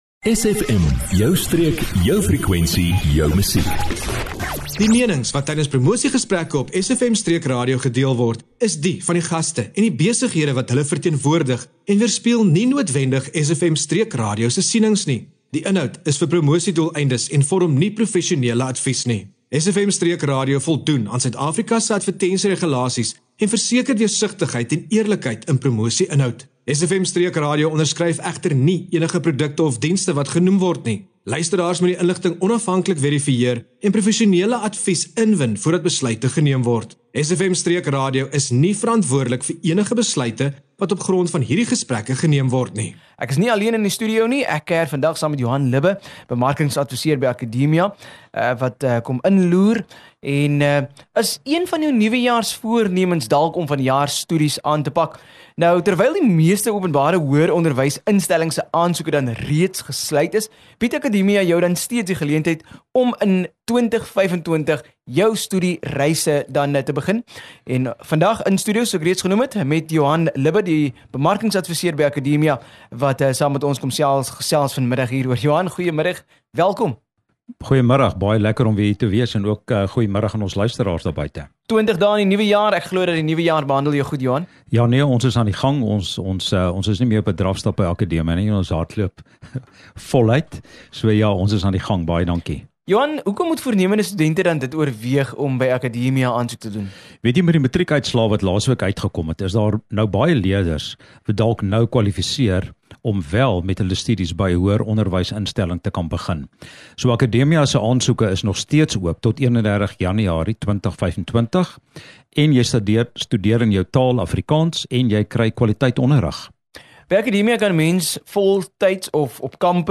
SFM Allegaartjie van onderhoude